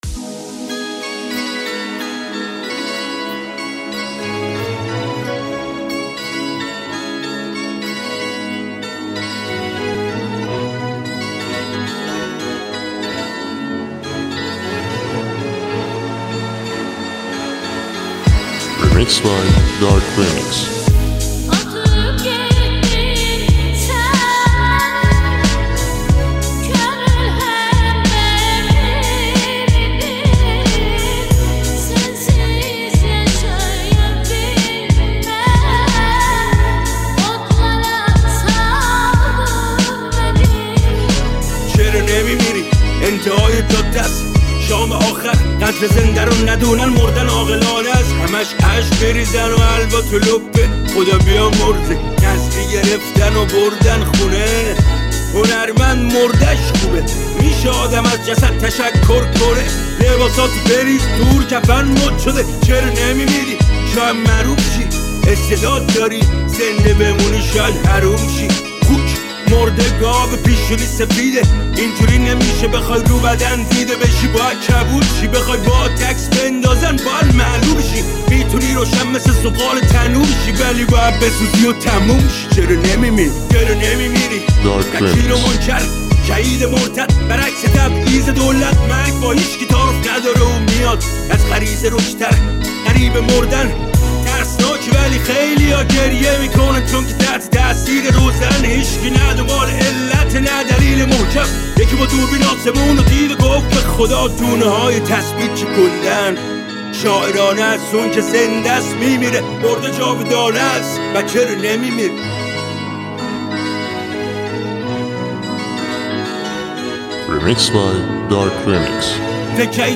ریمکیس رپ
ترکیبی با آهنگ ترکی